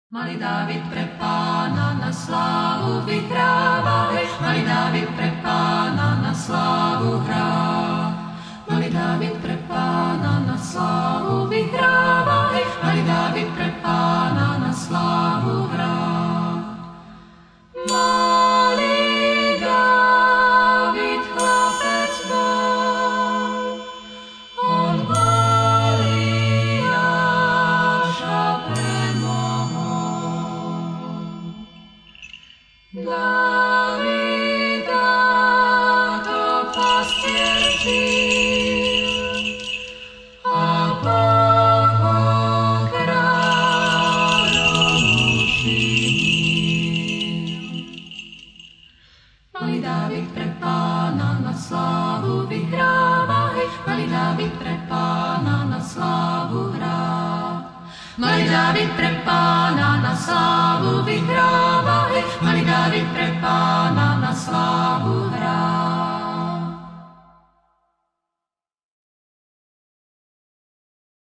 Spievajme: